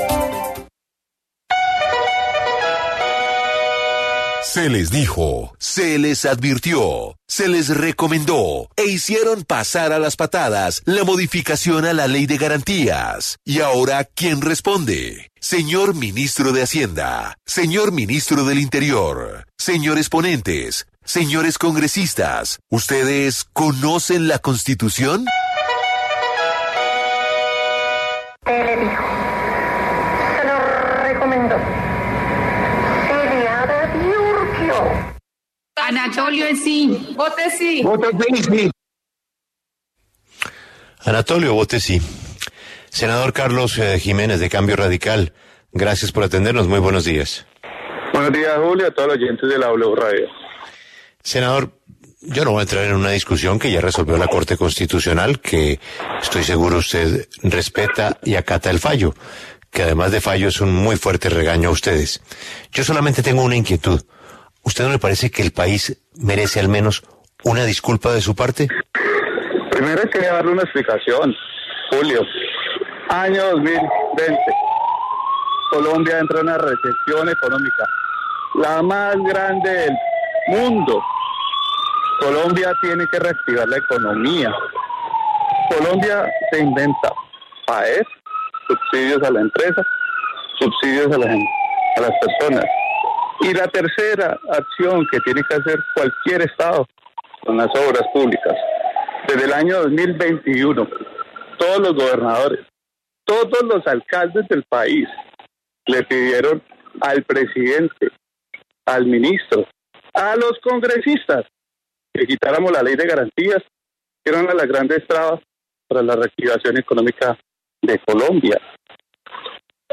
En diálogo con La W, el senador Carlos Jiménez se pronunció sobre la decisión de la Corte Constitucional de tumbar la modificación a la Ley de Garantías.